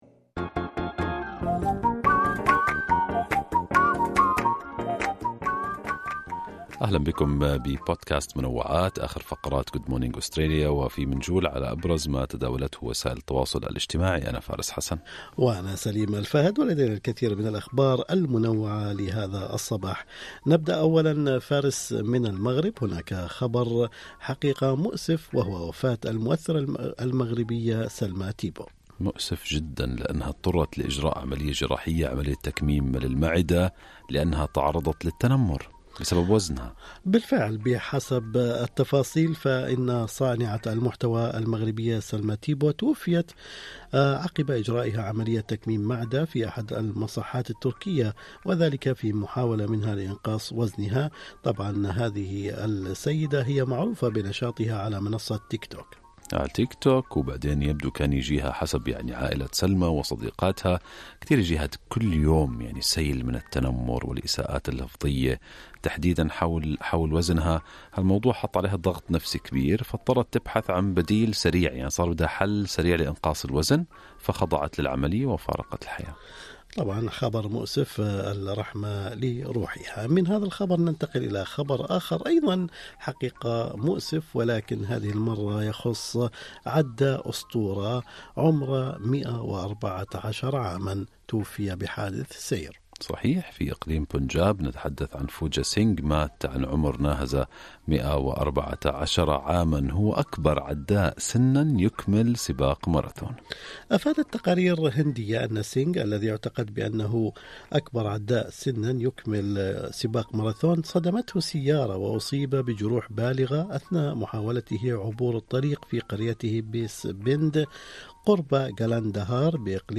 نقدم لكم فقرة المنوعات من برنامج Good Morning Australia التي تحمل إليكم بعض الأخبار والمواضيع الأكثر رواجًا على مواقع التواصل الاجتماعي.